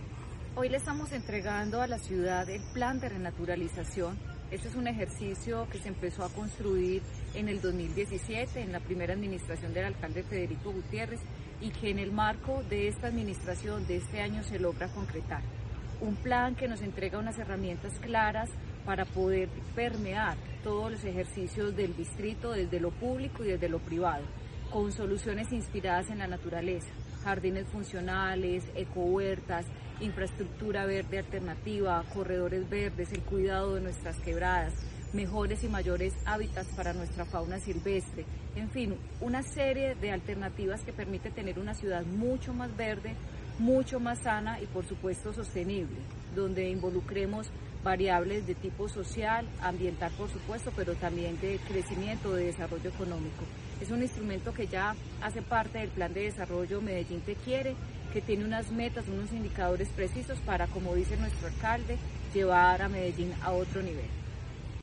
Palabras de Ana Ligia Mora, secretaria de Medio Ambiente Para enfrentar desafíos ambientales, el Distrito fortalece su compromiso con el Plan de Renaturalización.